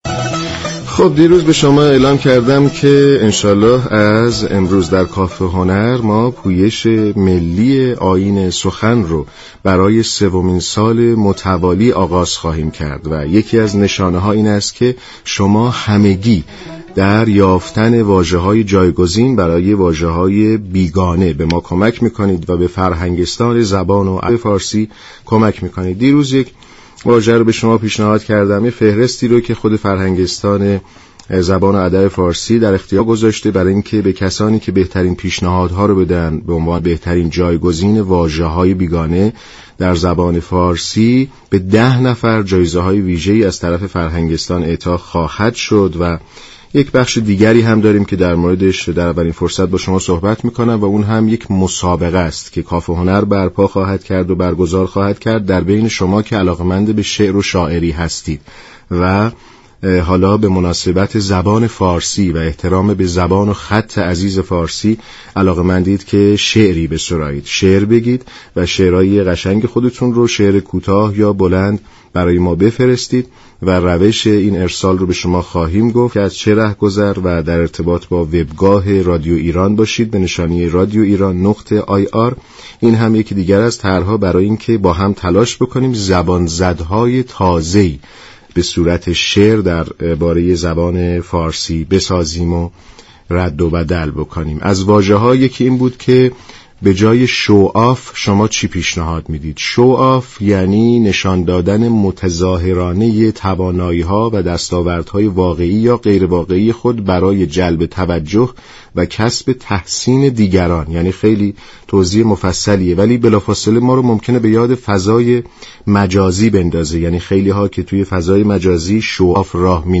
همچنین با توجه به روز 25 اردیبهشت كه روز پاسداشت زبان فارسی و بزرگداشت حكیم ابوالقاسم فردوسی است در همین زمینه بحث كارشناسی را با مدرس این حوزه خواهد داشت.